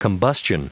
Prononciation du mot combustion en anglais (fichier audio)
Prononciation du mot : combustion